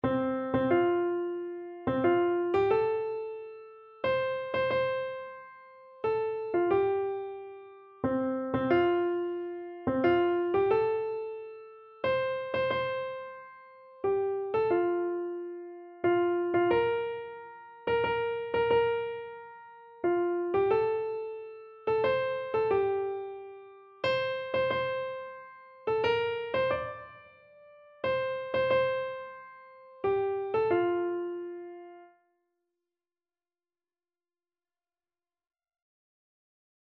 Free Sheet music for Keyboard (Melody and Chords)
ANdante =90
3/4 (View more 3/4 Music)
Keyboard  (View more Easy Keyboard Music)
Classical (View more Classical Keyboard Music)